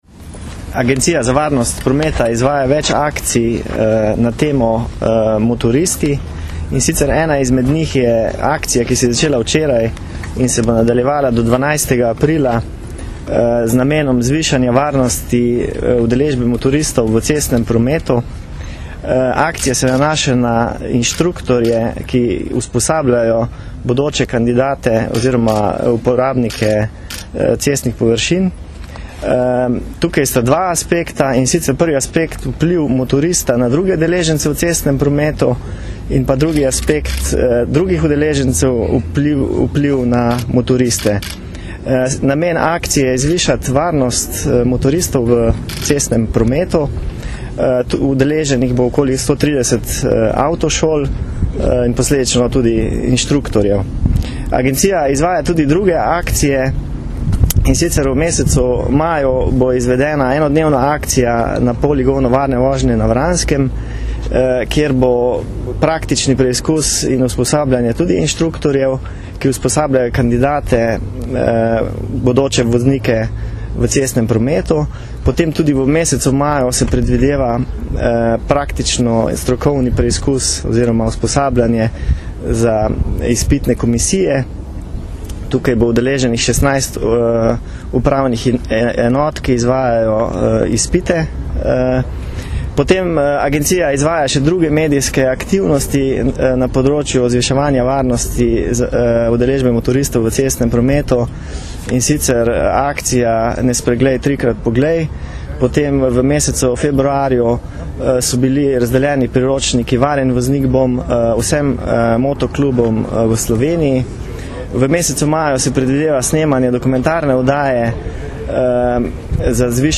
Ob začetku motoristične sezone predstavili akcijo za večjo varnost motoristov - informacija z novinarske konference
izjava (mp3)